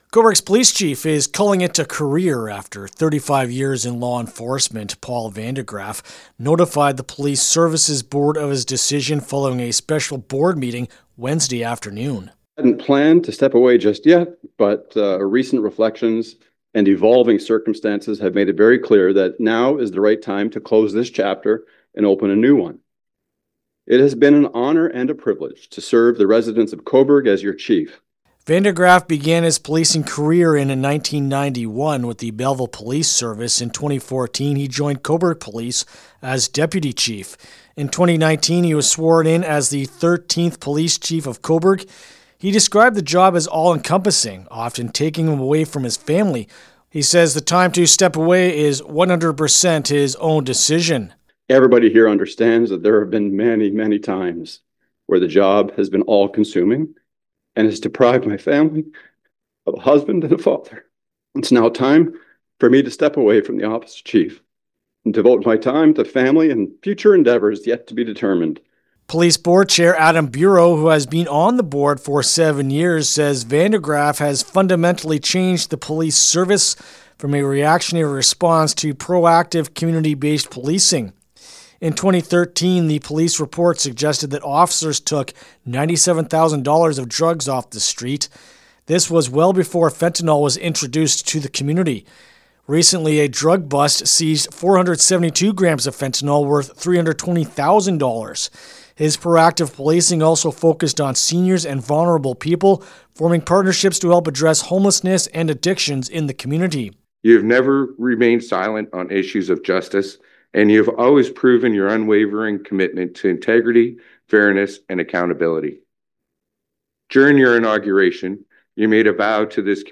Cobourg-Police-Chief-Retirement-Report-LJI.mp3